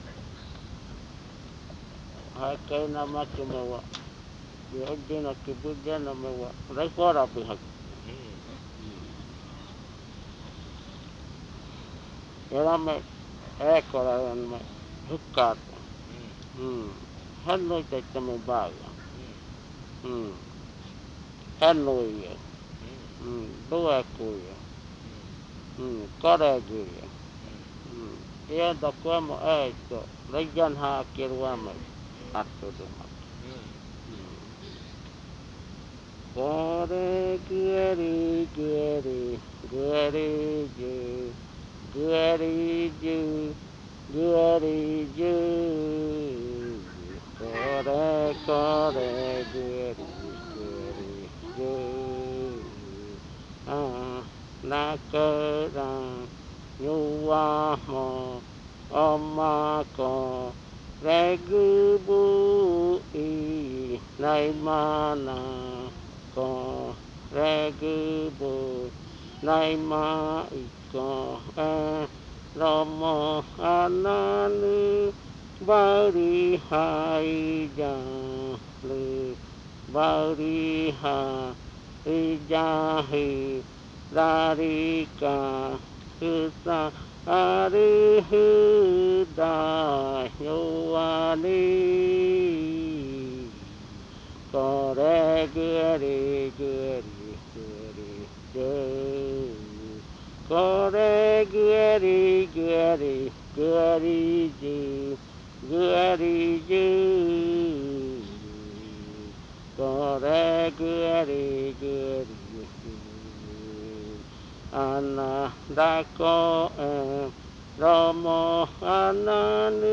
Mourning chant
Adofikɨ (Cordillera), río Igaraparaná, Amazonas
Canto de luto empleado por los Murui del Caraparaná.
Mourning chant used by the Murui of Caraparaná. In this ritual a special very thick cahuana was drunk (raɨforabɨ) and rattle spears, called garada, were used.